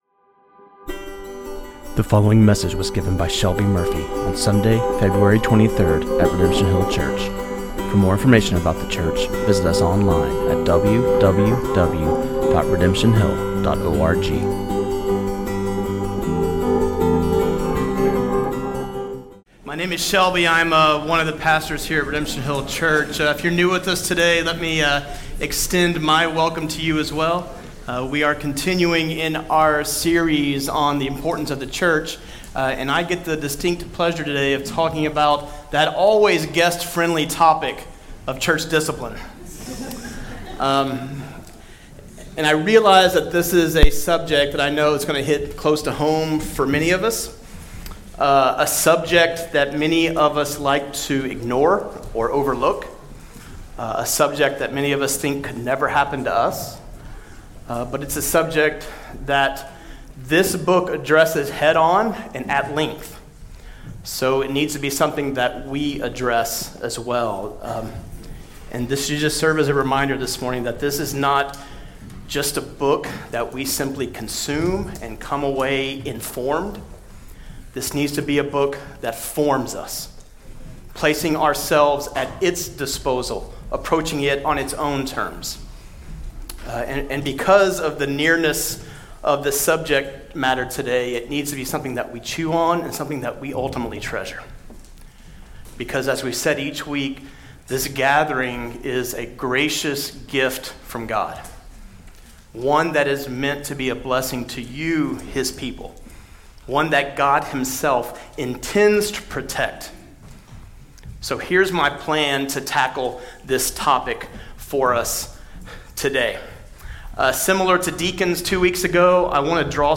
sermon
at Redemption Hill Church